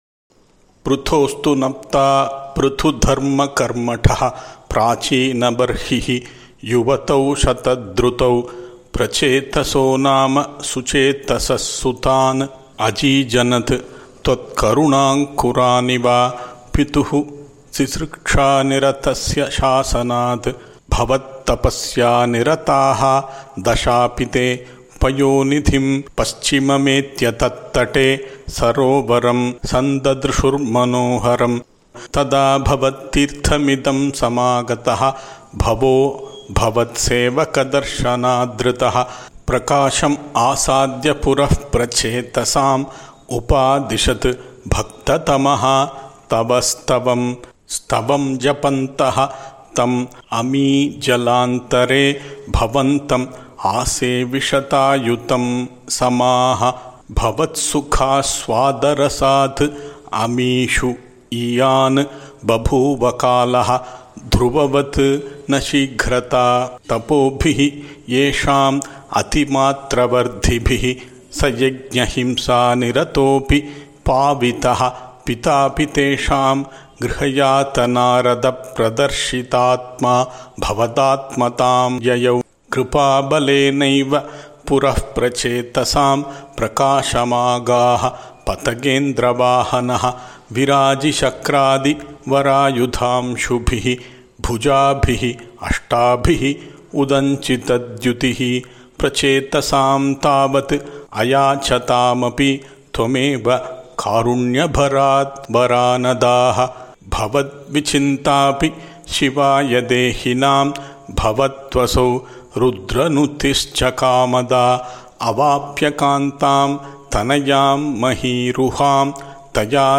I have also attempted to chant that way in the recording above.